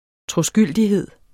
Udtale [ tʁoˈsgylˀdiˌheðˀ ]